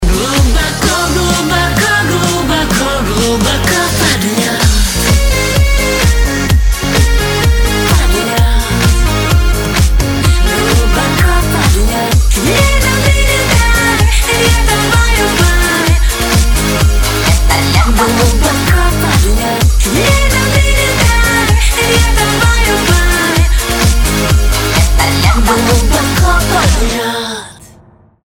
поп
женский вокал